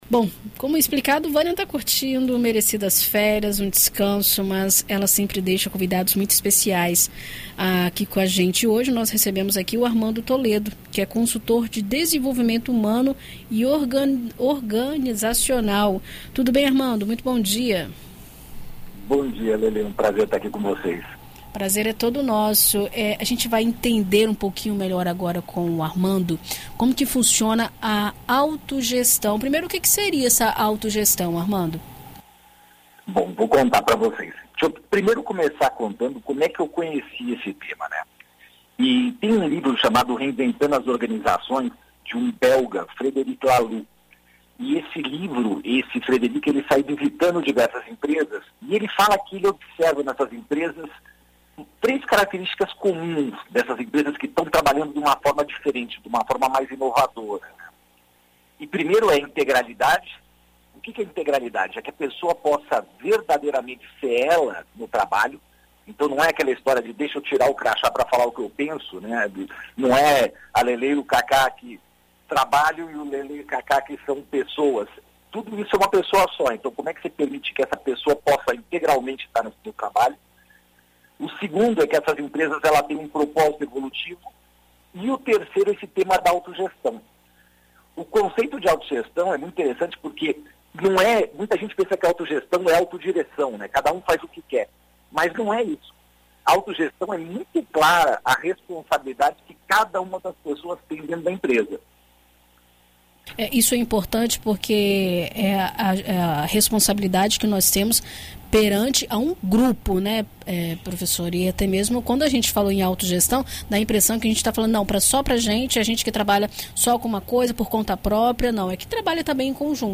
Na coluna ” Vida e Carreira” desta quarta-feira (11)  na BandNews FM ES